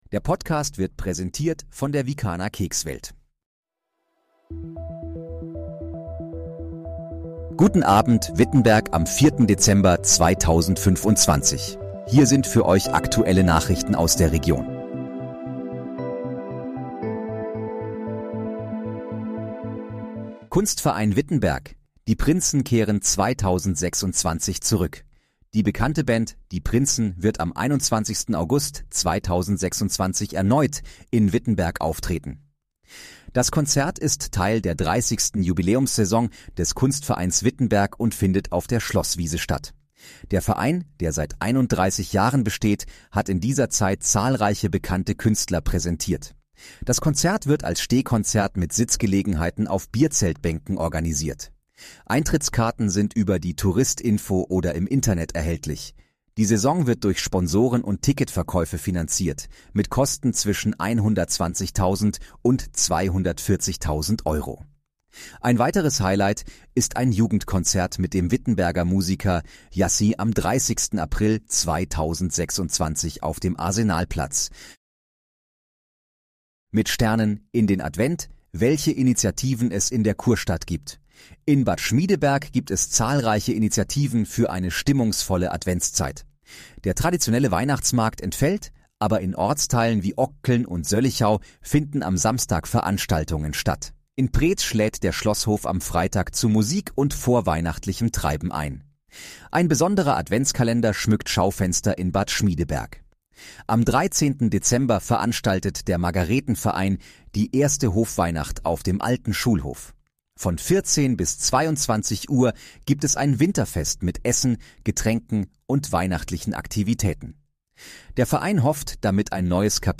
Guten Abend, Wittenberg: Aktuelle Nachrichten vom 04.12.2025, erstellt mit KI-Unterstützung
Nachrichten